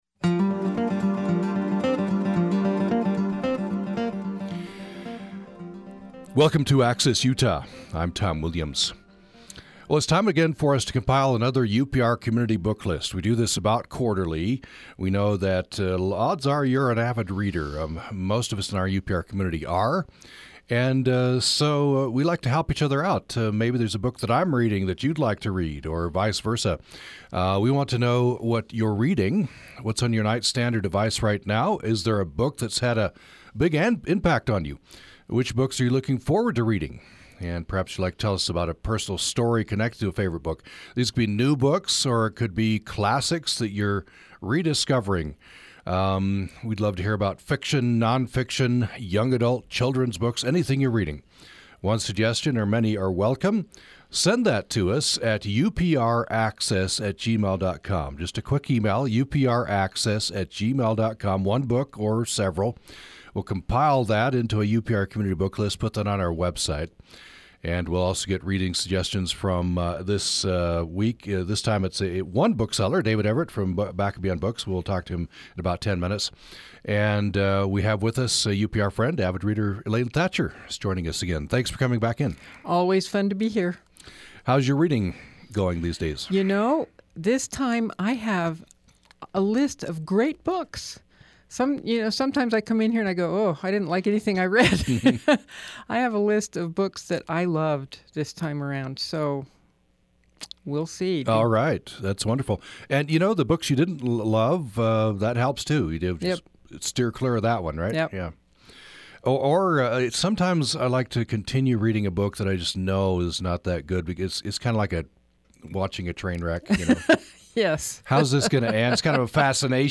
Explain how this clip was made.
Access Utah is UPR's original program focusing on the things that matter to Utah. The hour-long show airs live Monday-Thursday at 9:00 a.m. Access Utah covers everything from pets to politics in a range of formats from in-depth interviews to call-in shows.